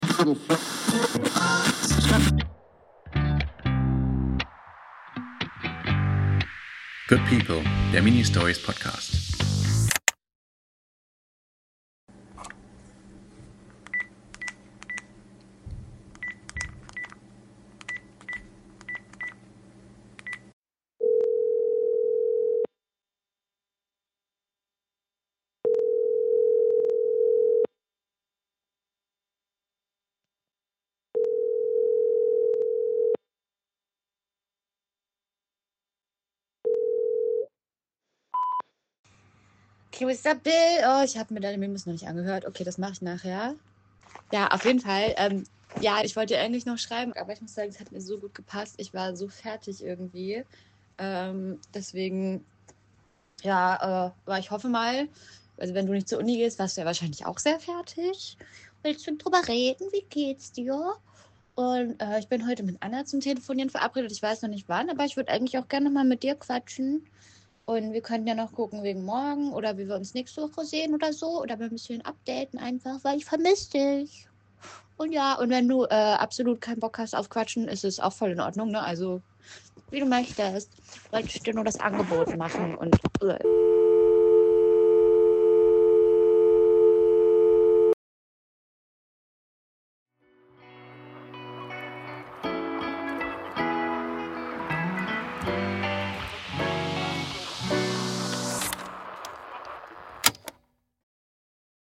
Es tutet. Einmal, zweimal, dreimal – dann der Signalton. Eine Stimme, hell und aufmerksam.
Dann ein Rascheln, ein Geräusch – und der Anruf bricht ab.